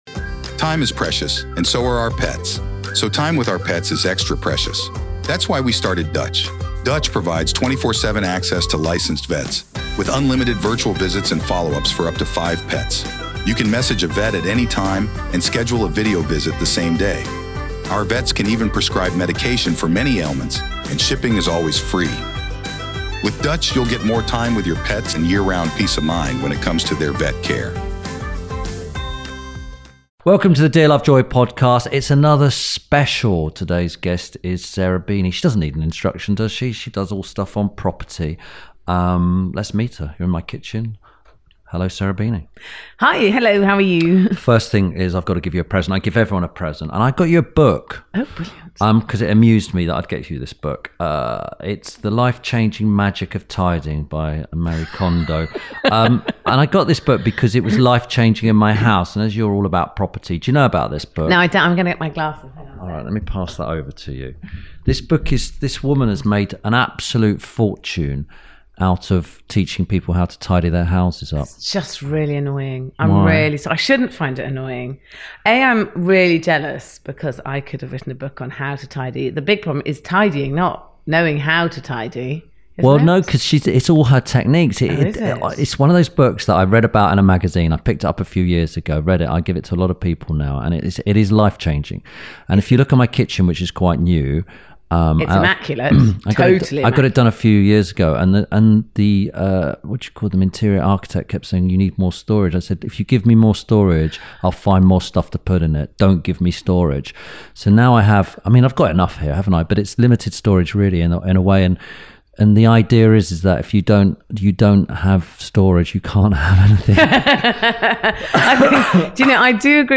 This week Tim Lovejoy talks to presenter, property developer and entrepreneur Sarah Beeny. They discuss Sarah’s early jobs, how she came to be a TV presenter, and making money in property.